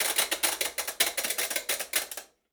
Washing Machine Dial Sound
household